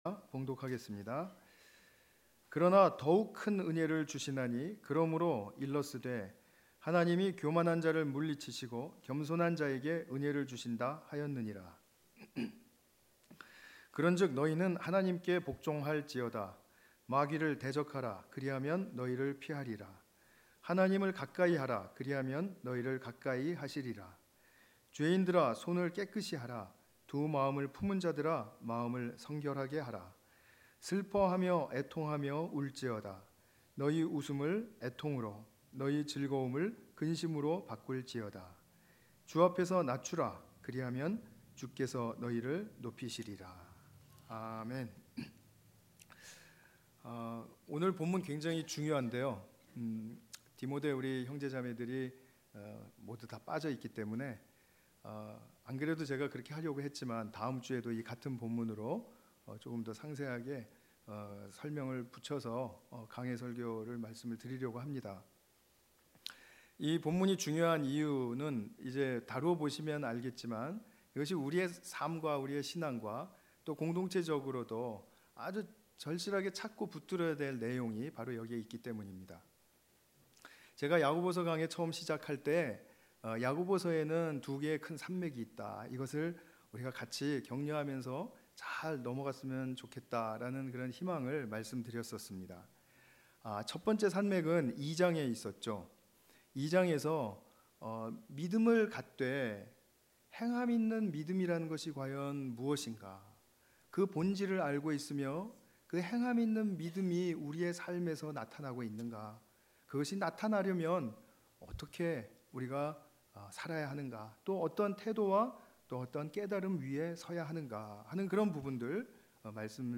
관련 Tagged with 주일예배 Audio (MP3) 69 MB PDF 287 KB 이전 야고보서 (14) - 경건의 피상을 넘어야 사랑이 보입니다.